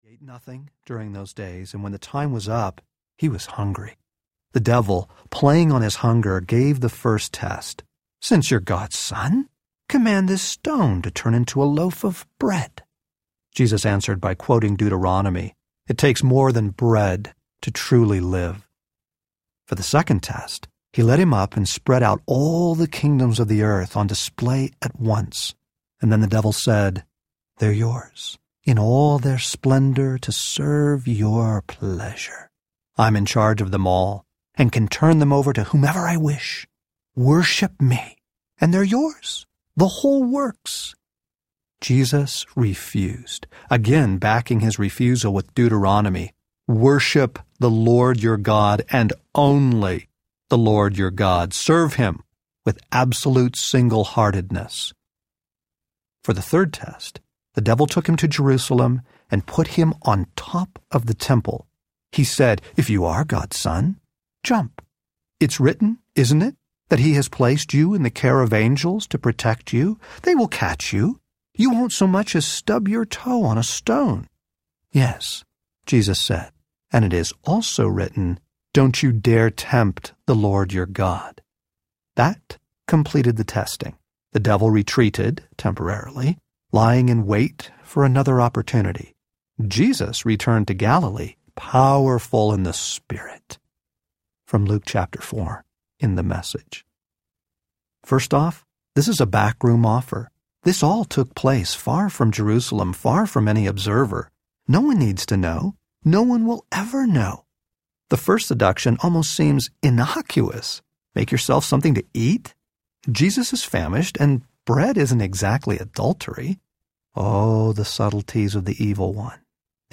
The Utter Relief of Holiness Audiobook